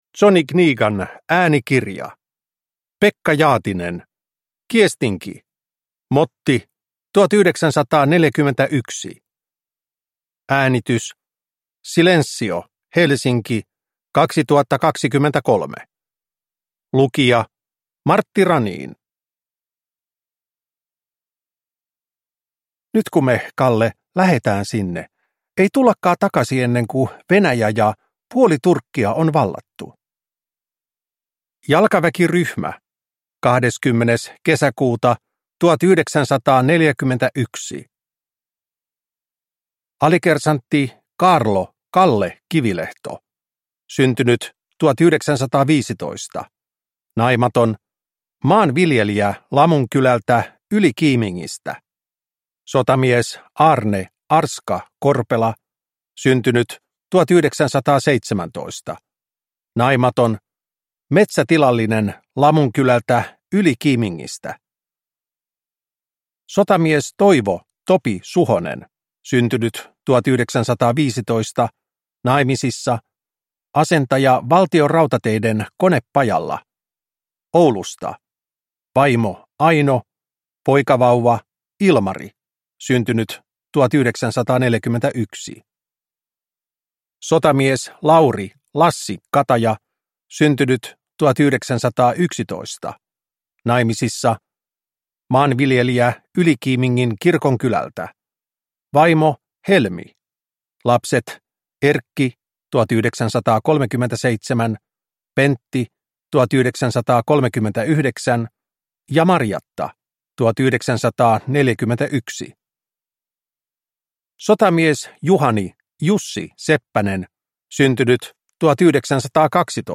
Kiestinki – Ljudbok – Laddas ner